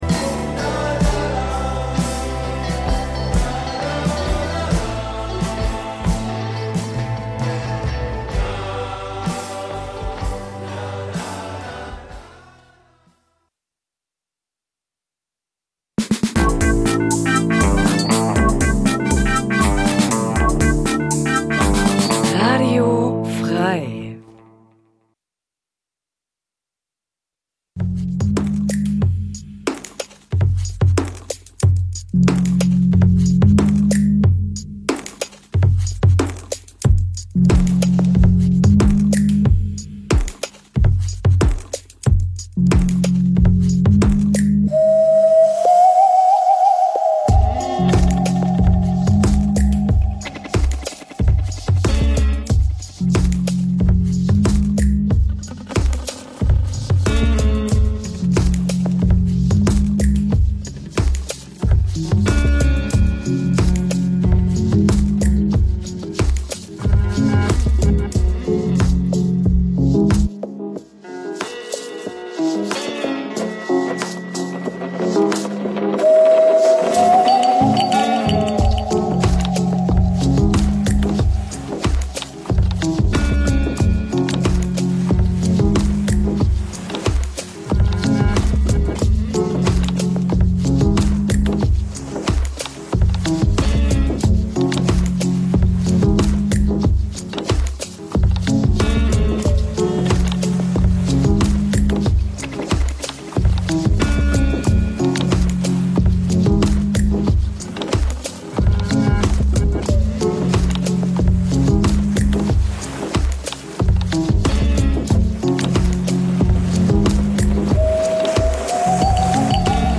Musiksendung Dein Browser kann kein HTML5-Audio.